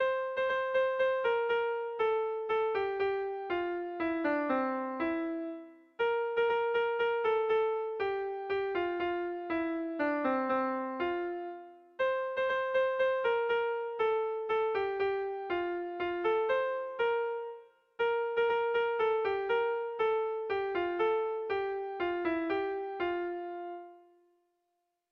Dantzakoa
Zortziko berdina, 4 puntuz eta 8 silabaz (hg) / Lau puntuko berdina, 16 silabaz (ip)